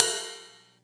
VEC3 Ride
VEC3 Cymbals Ride 05.wav